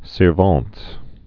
(sîr-väɴt, sər-vĕnt) also sir·ven·tes (sər-vĕntĭs, -vĕnts)